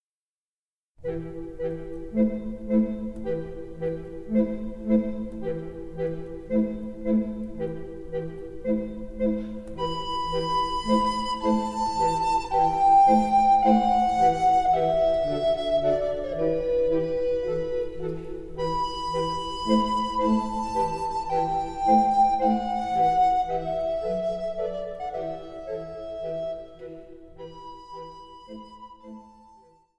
リコーダーカルテットとオカリナ＆ハープの響き